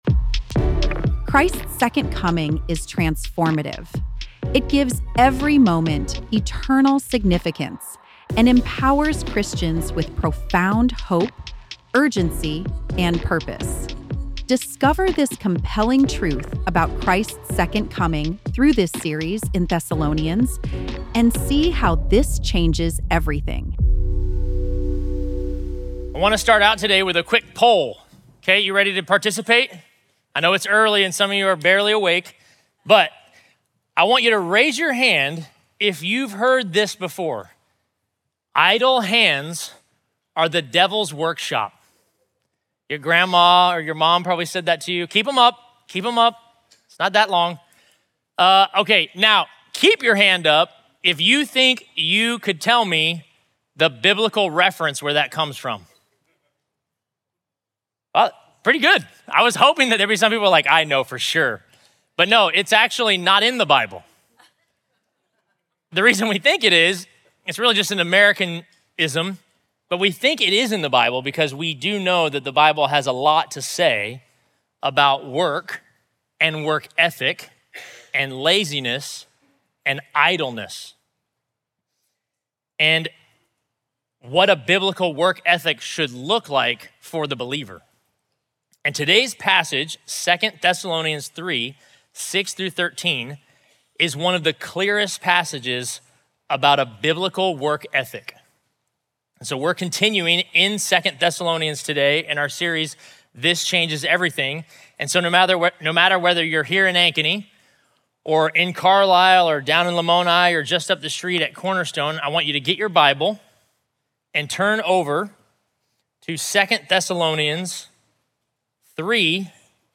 Listen to the latest sermon and learn more about this preaching series here.